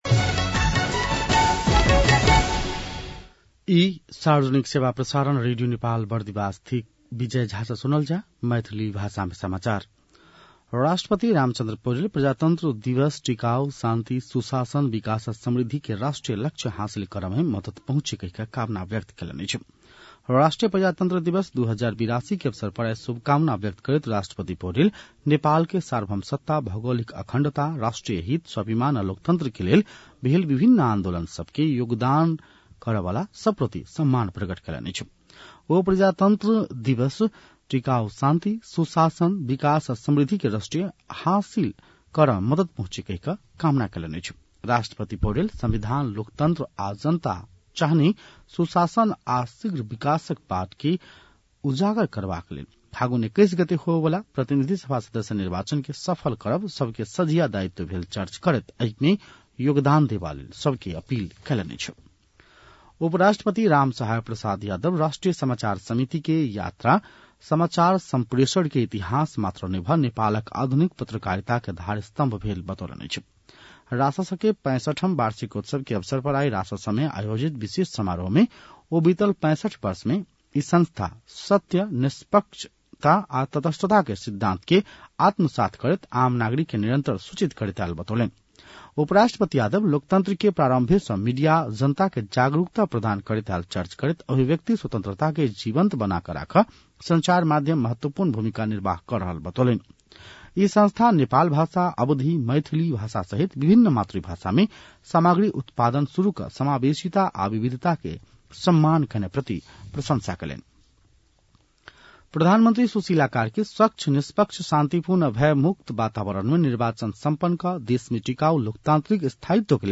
मैथिली भाषामा समाचार : ७ फागुन , २०८२
6.-pm-maithali-news-1-8.mp3